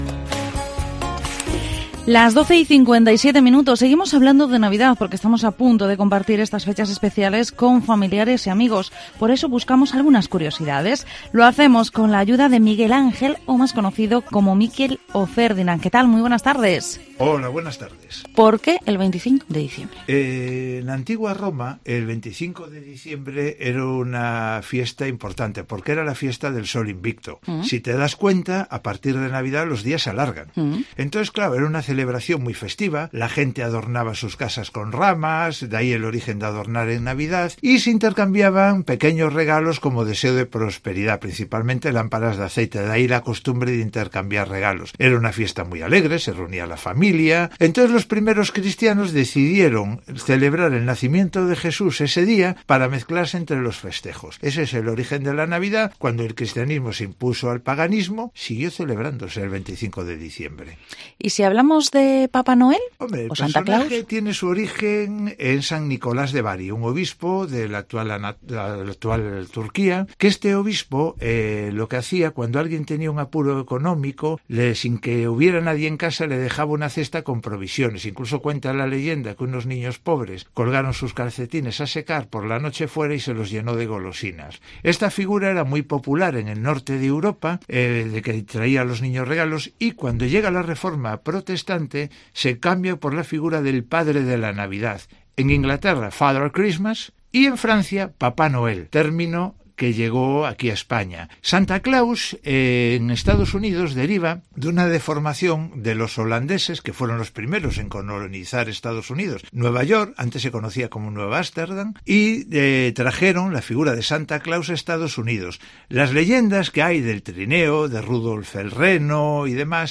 Vigo Entrevista ¿Por qué celebramos la Navidad el 25 de diciembre?